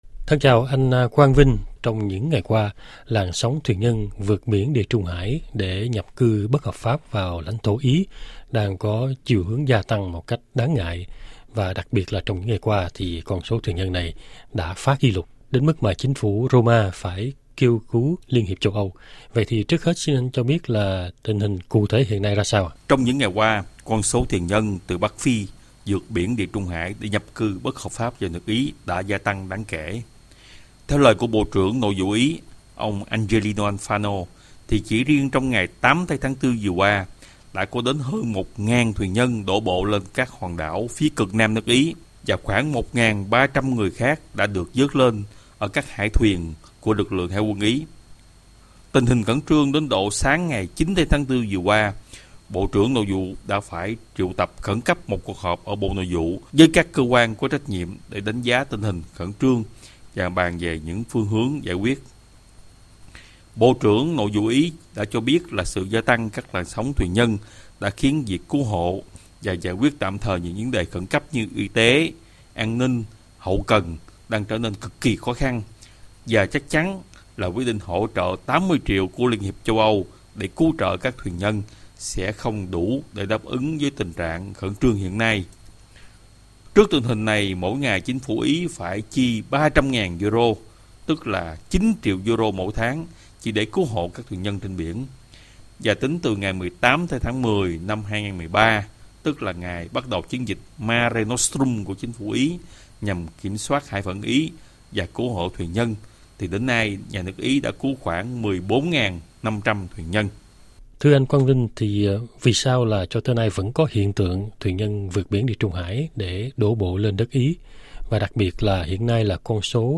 tags: Châu Âu - Phỏng vấn - Quốc tế - thuyền nhân - Xã hội - Ý